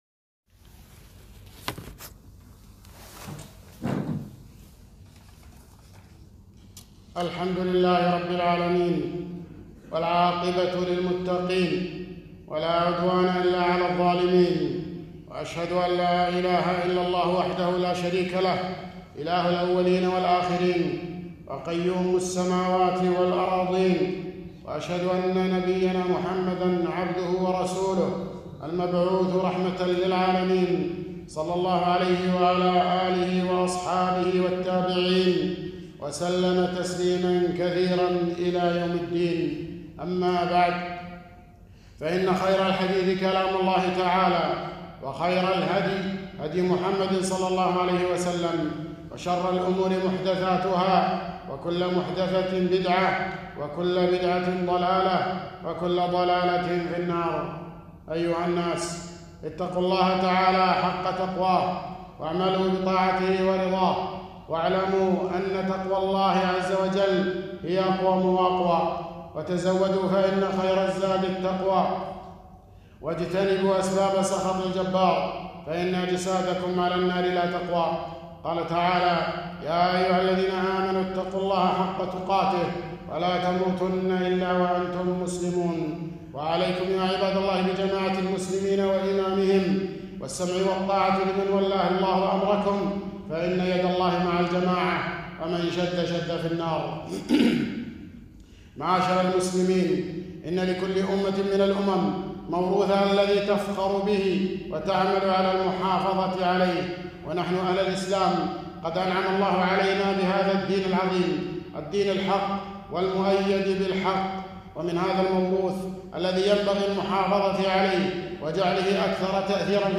خطبة - التأريخ الهجري و شهر الله المحرم